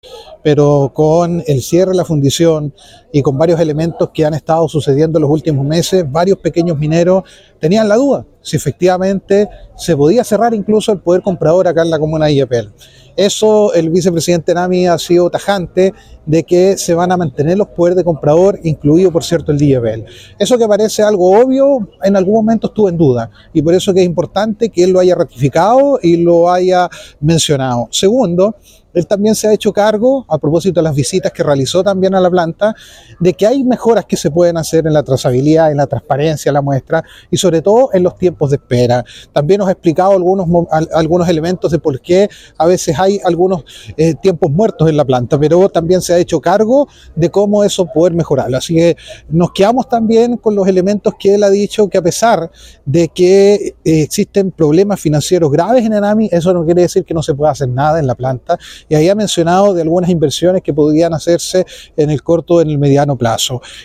El presidente de la Mesa Minera illapelina, alcalde Denis Cortés Aguilera, valoró el anuncio.